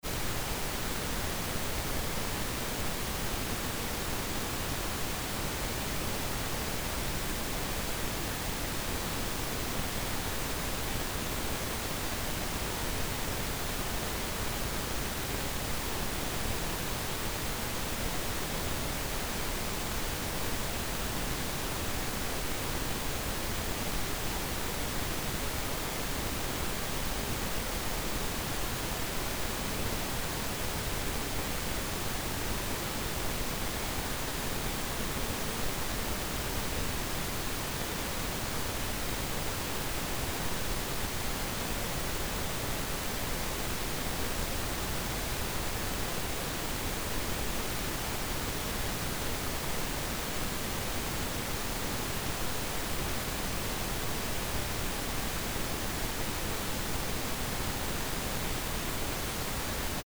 Attached is pink noise at -20dBFS and it reads -20 as the original.wav file generated in Adobe Audition 1.5. In the absence of a sound level meter it should play quite loudly being louder than you would have the telly for a soap. It should be intrusive enough to be slightly difficult to talk over.